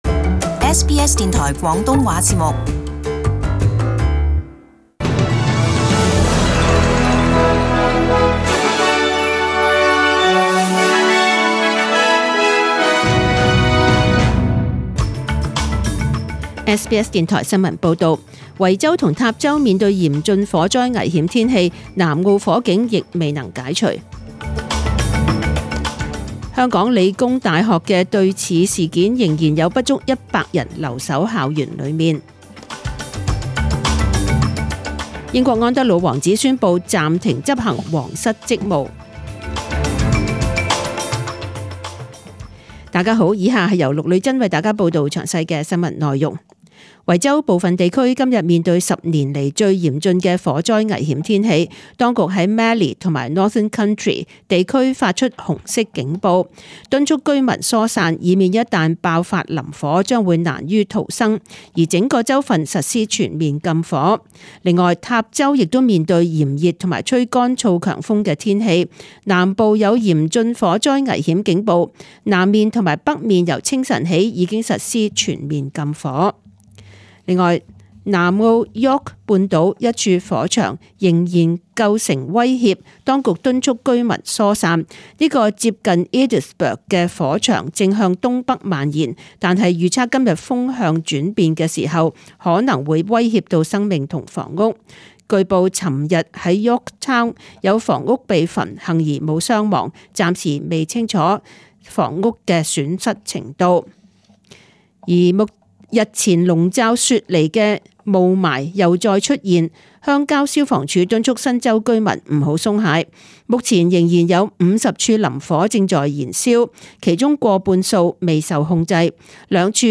請收聽本台為大家準備的詳盡早晨新聞